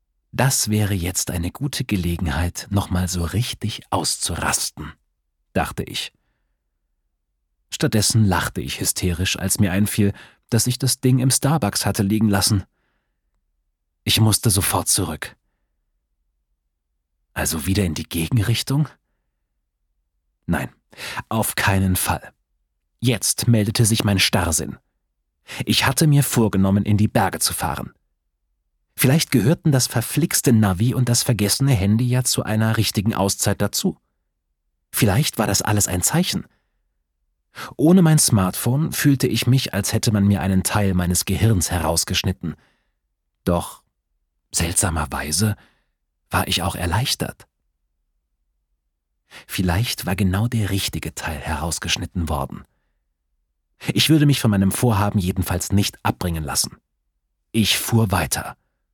Format: Download-Hörbuch  (MP3)
Fassung: Ungekürzte Ausgabe